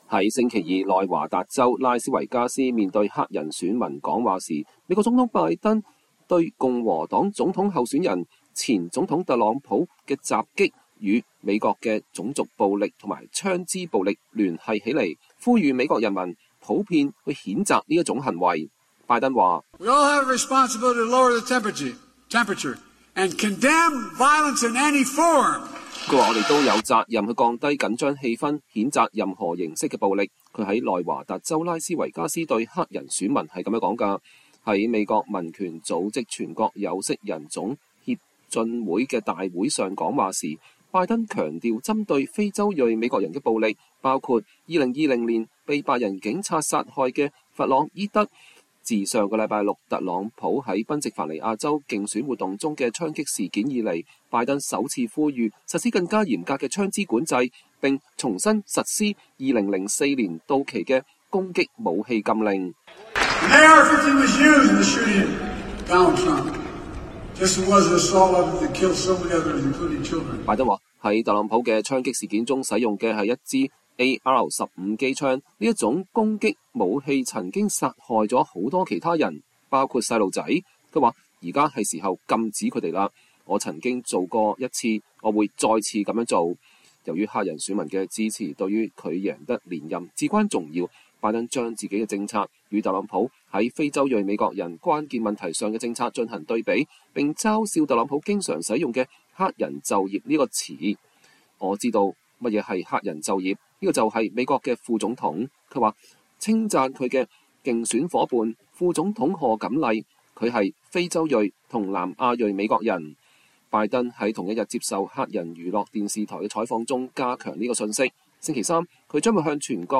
在週二內華達州拉斯維加斯面對黑人選民講話時，美國總統拜登(Joe Biden)將對共和黨總統候選人、前總統特朗普(Donald Trump)的襲擊與美國的種族暴力和槍枝暴力聯繫起來，呼籲美國人民普遍譴責這種行為。